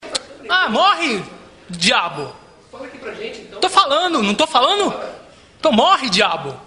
Presidiário transtornado esbraveja com repórteres: Ah, morre, diabo, tô falando, não tô falando? Então morre, diabo!